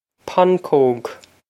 pancóg pon-kogue
Pronunciation for how to say
This is an approximate phonetic pronunciation of the phrase.